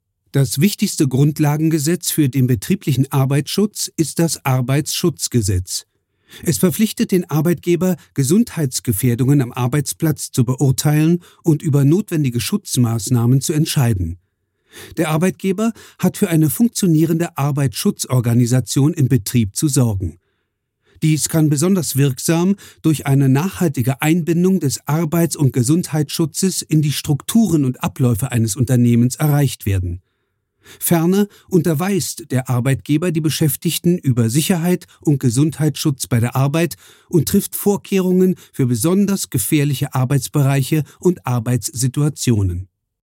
Gelernter Schauspieler. Glaubwürdig, ausdrucksstark, überzeugend, stimmlich variabel. Dialektfreie Mittellage, weich & samtig.
Sprechprobe: eLearning (Muttersprache):
Trained german actor. Credible, expressive, convincing, vocally variable. Dialect-free mid-range, soft & velvety.